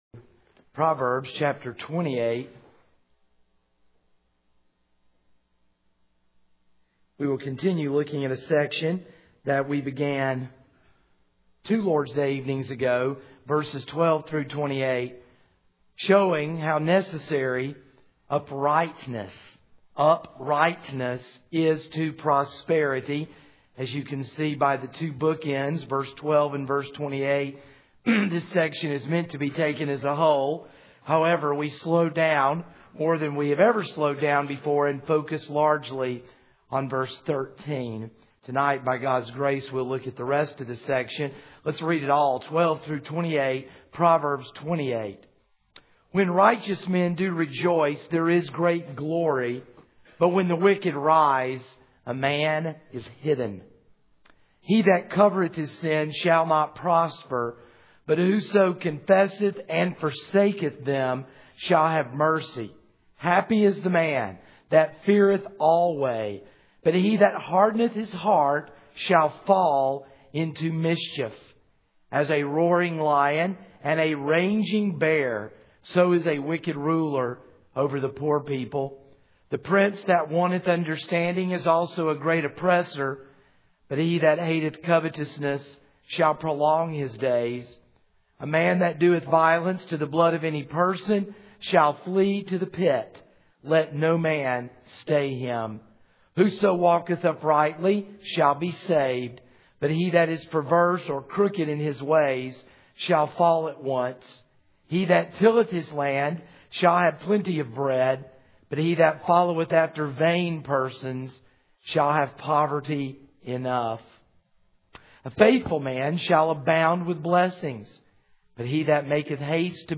This is a sermon on Proverbs 28:12-28 (Part 2 of 2).